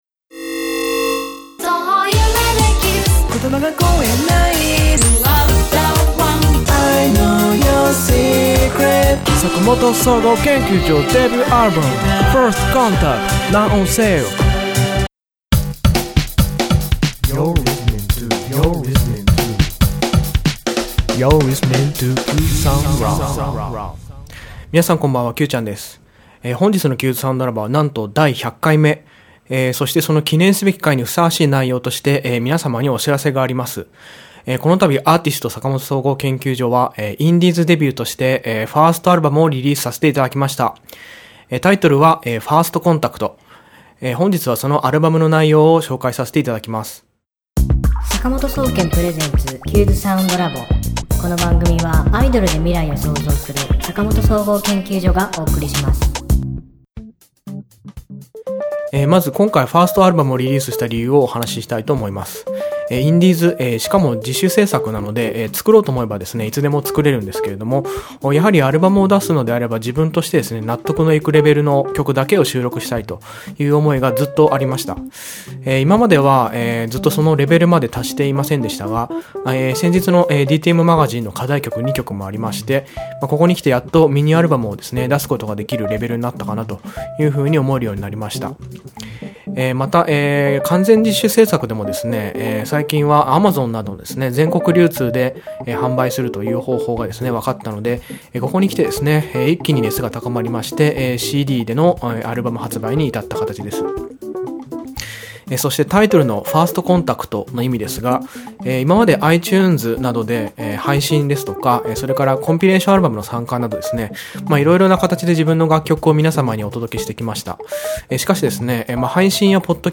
今週の挿入歌